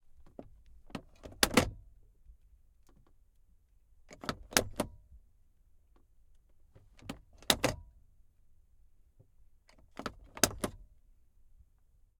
John_Deere_Tractor_t12_Var_SFX_Blinker_Lever_XY_RSM191.ogg